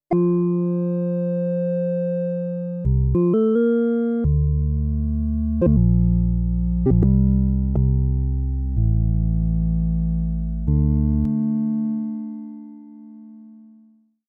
console bootup sound